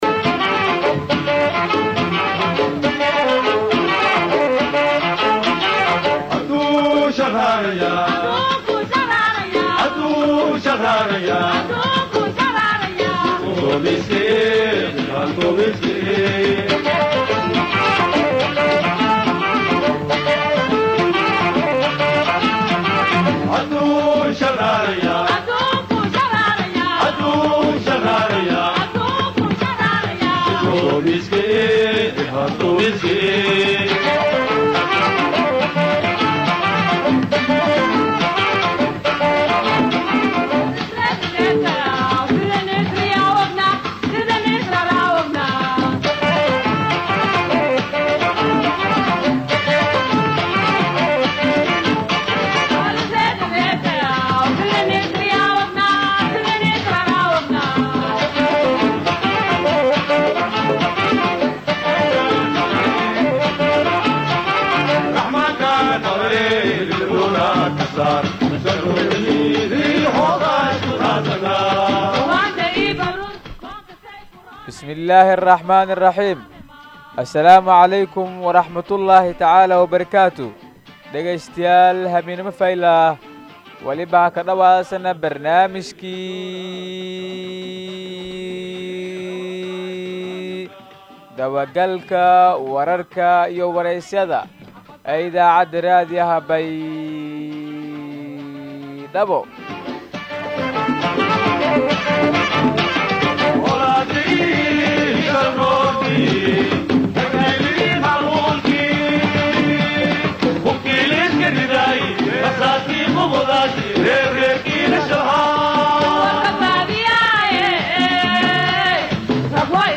BAYDHABO–BMC:–Dhageystayaasha Radio Baidoa ee ku xiran Website-ka Idaacada Waxaan halkaan ugu soo gudbineynaa Barnaamijka Dabagalka Wararka iyo Wareysiyada ee ka baxay Radio Baidoa.